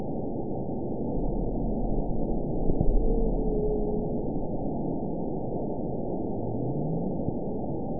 event 922142 date 12/27/24 time 09:50:46 GMT (5 months, 3 weeks ago) score 9.51 location TSS-AB04 detected by nrw target species NRW annotations +NRW Spectrogram: Frequency (kHz) vs. Time (s) audio not available .wav